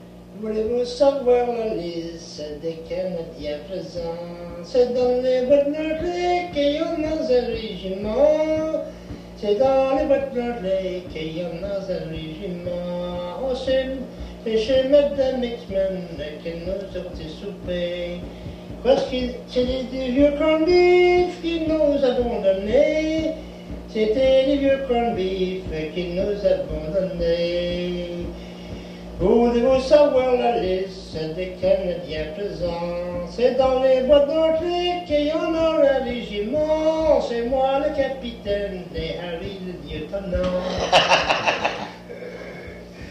Emplacement Cap St-Georges